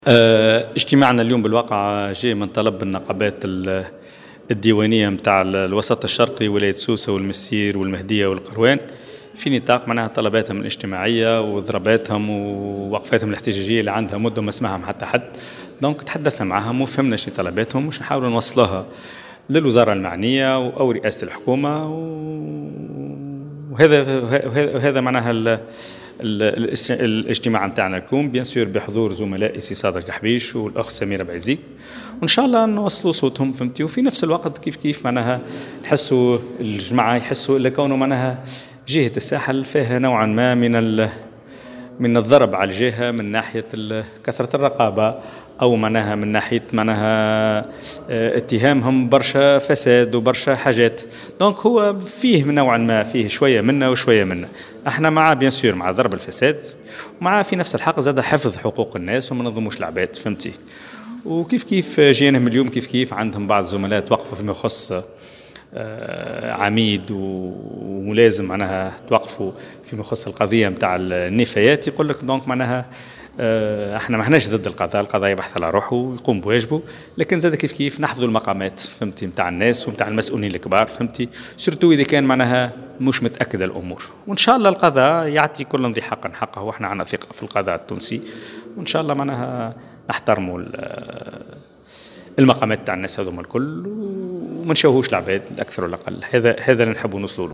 وقال النائب حافظ الزواري في تصريح للجوهرة اف أم، جاء بناءً على طلب من النقابات الديوانية بالوسط الشرقي (ولايات سوسة والمنستير والمهدية والقيروان)، حيث وقع الإصغاء إلى مشاغلهم، واعدا بتبليغ أصواتهم إلى السلط المعنية من سلطة الإشراف إلى رئاسة الحكومة.